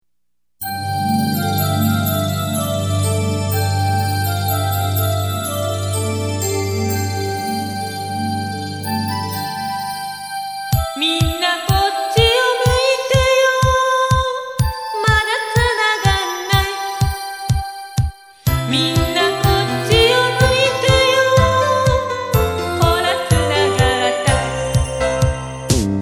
イントロ　　　　ＭＰ３ファィル・・・420ＫＢ